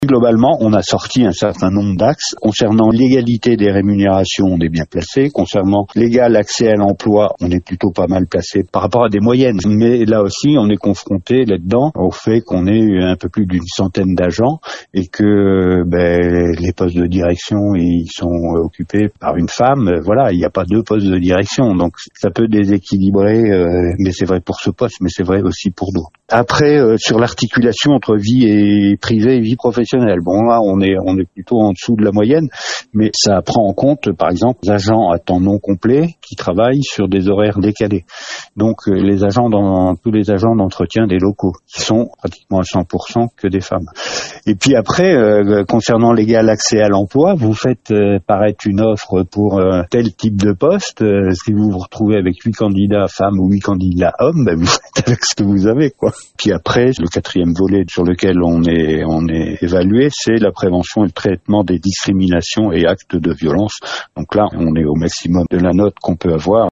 Il porte notamment sur la réduction des écarts de rémunération et l’égal accès à l’emploi. Le président de la CdC Aunis Sud Jean Gorioux nous en précise le contenu :